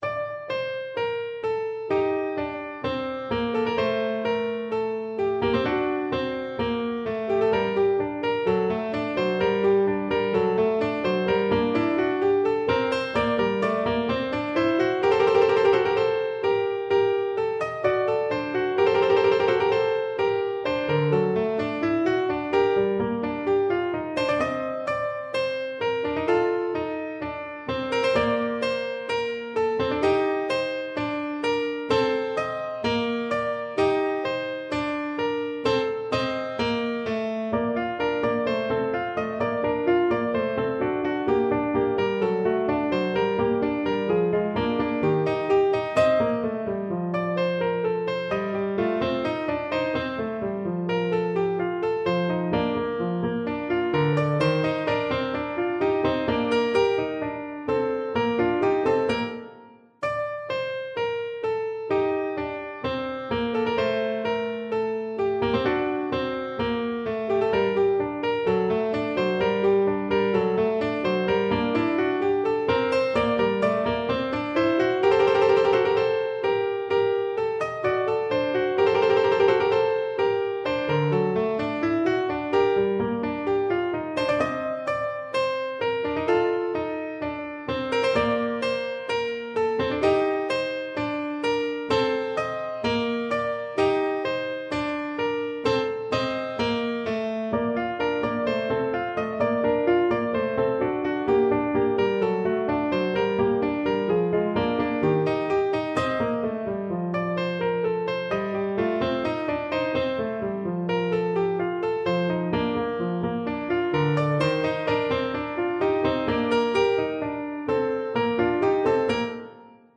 This sheet music is arranged for Violin and Viola.
» 442Hz